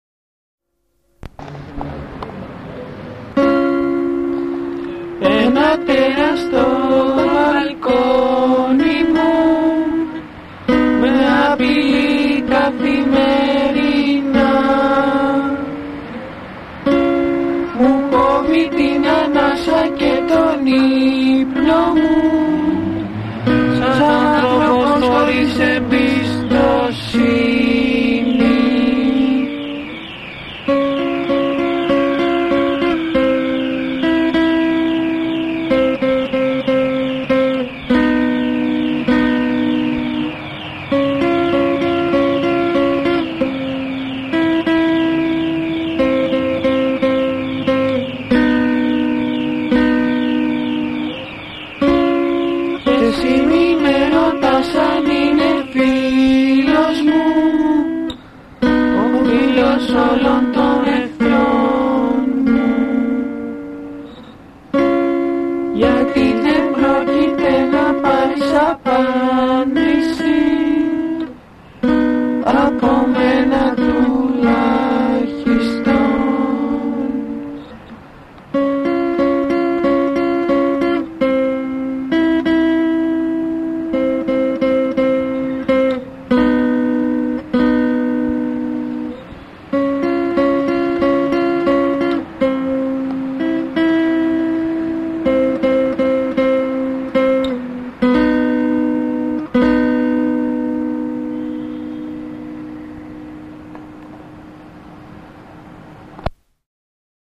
Σημείωση: Η κακή ποιότητα του ήχου δεν οφείλεται στα mp3.